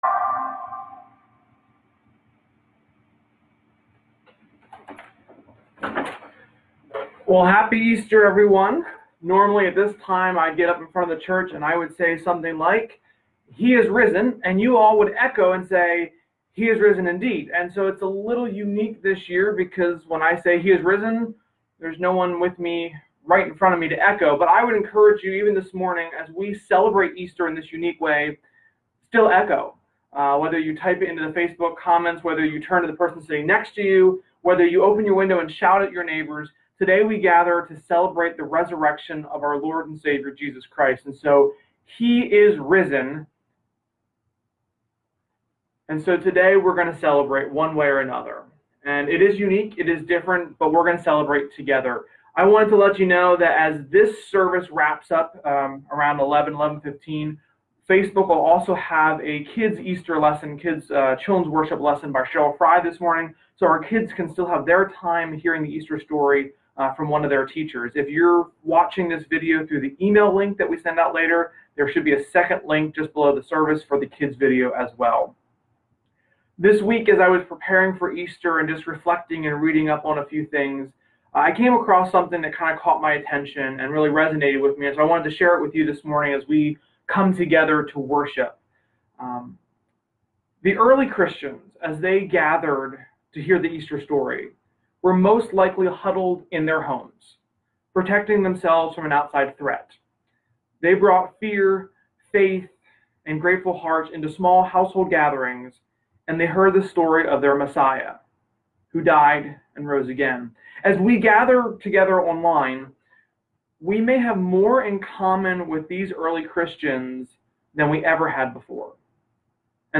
Easter-Worship-Service-4-mp3cut.net_.mp3